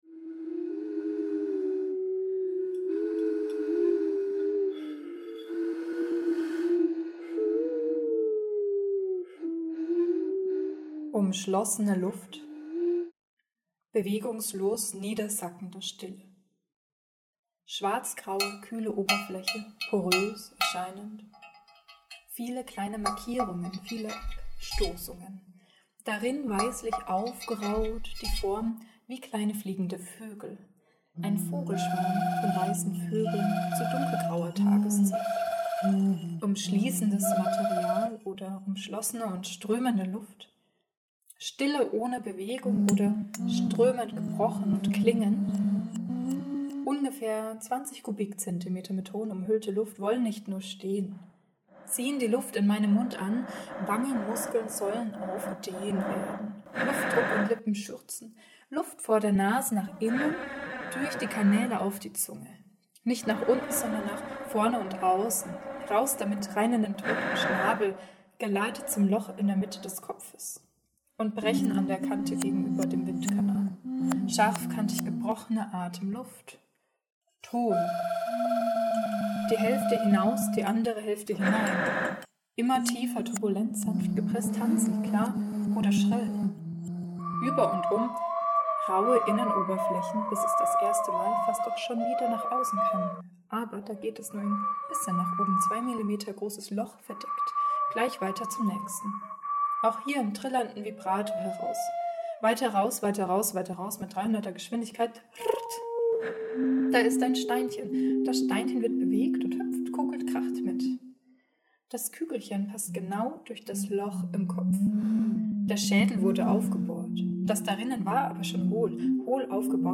Flöten als Ausdruck des Forschens nach den Klängen verschiedener körperhafter Formen.